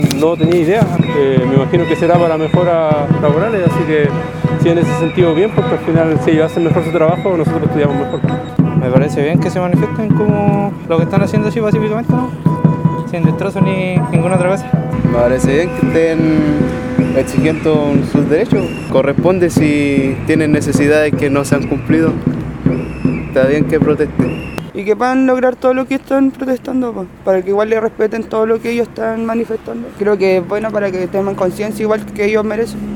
Distintos alumnos que conversaron con La Radio, dijeron estar de acuerdo con la huelga y la manifestación, de la cual se enteraron hoy al llegar a la sede.